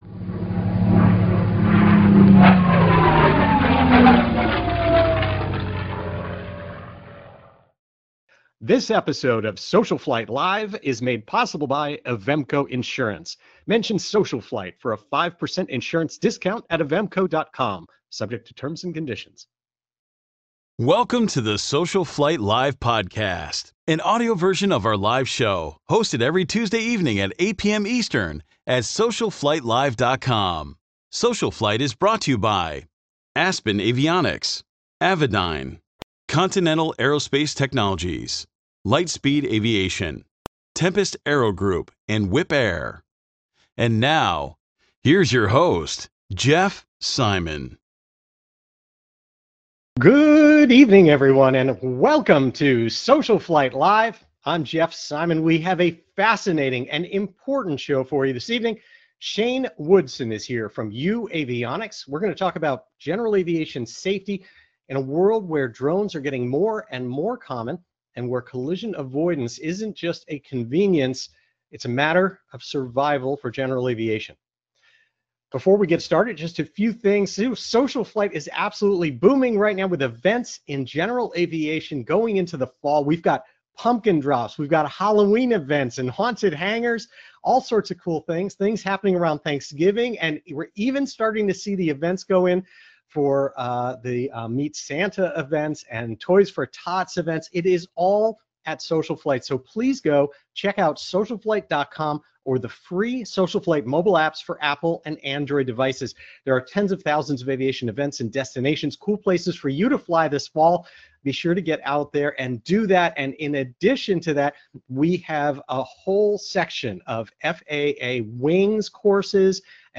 “SocialFlight Live!” is a live broadcast dedicated to supporting General Aviation pilots and enthusiasts during these challenging times.